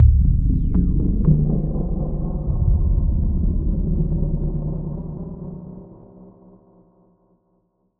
Low End 18.wav